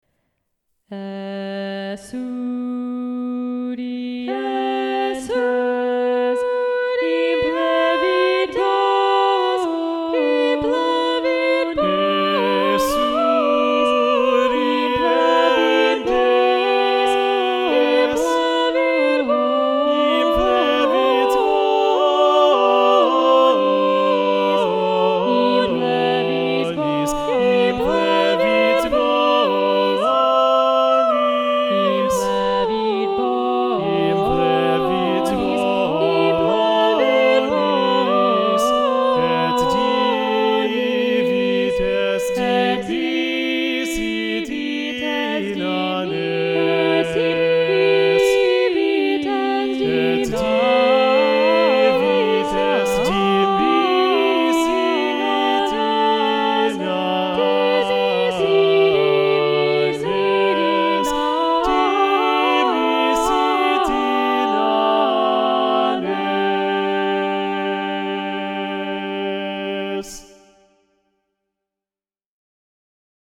Soprano, Alto & Bass